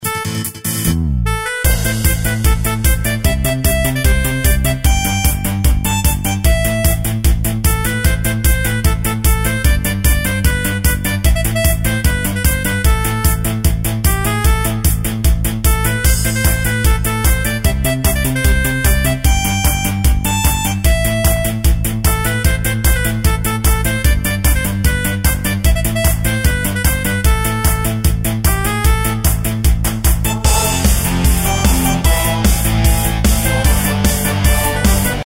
Tempo: 150 BPM.
MP3 with melody DEMO 30s (0.5 MB)zdarma